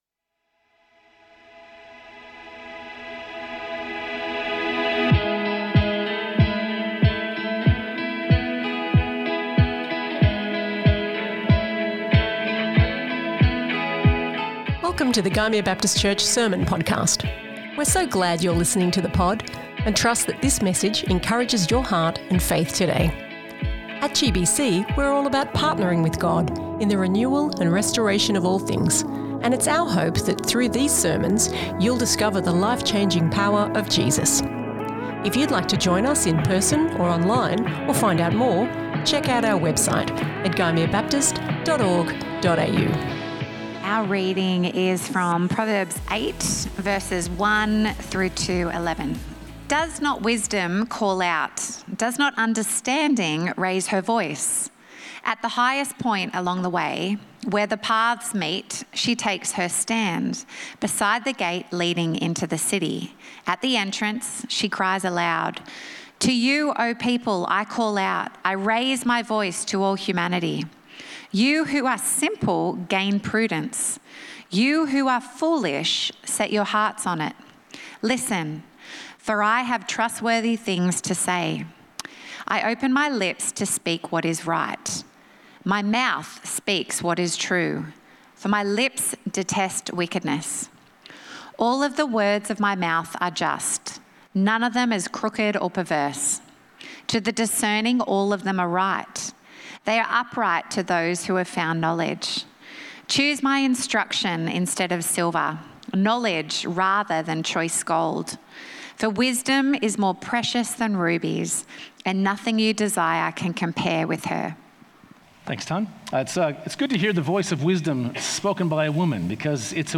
This is the last sermon in our series, 'Jesus and the Sydney Morning Herald'.